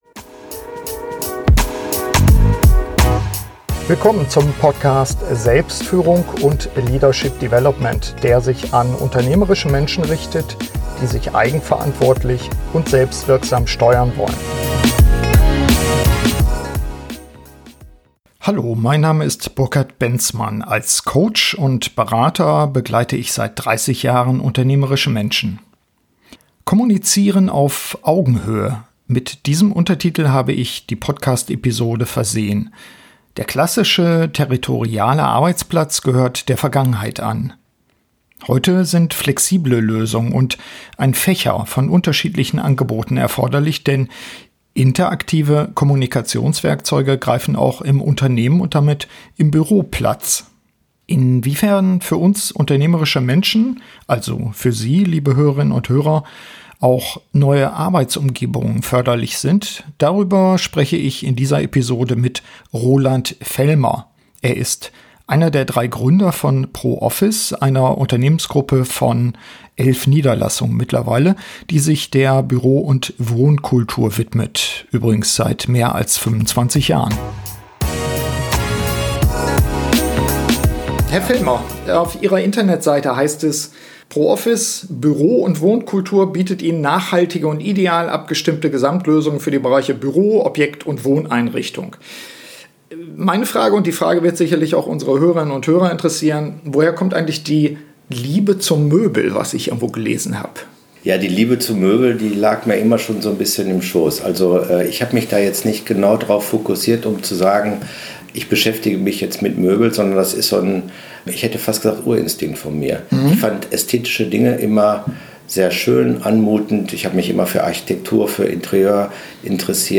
SF56 Renaissance des Büros - Interview